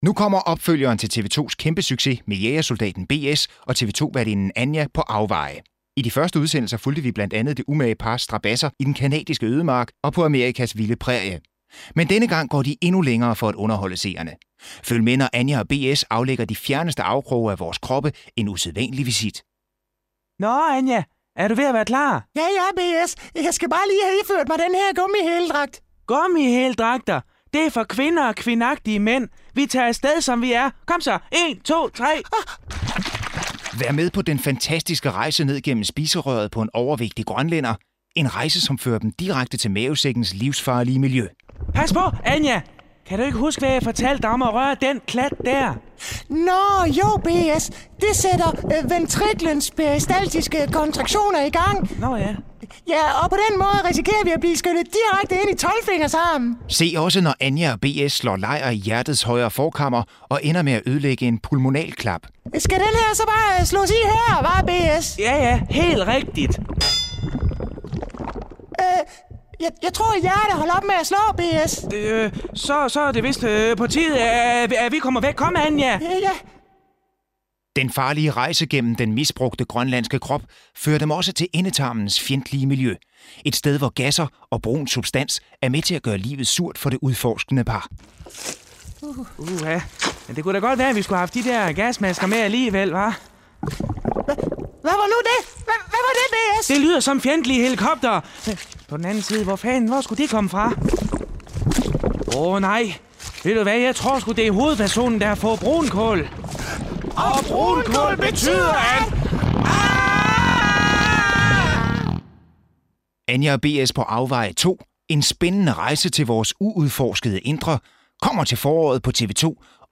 Det er her, du kan høre alle de gode, gamle indslag fra ANR's legendariske satireprogram.
For anden gang i Farlig Fredags historie blev redaktionen samlet til 3 timers "Farligt Nytår" nytårsaftensdag.